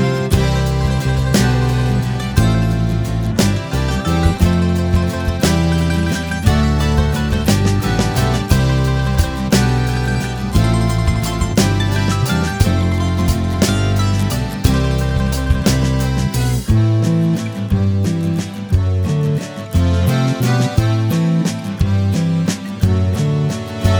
No Piano or Harmonica Version 1 Pop (1980s) 5:31 Buy £1.50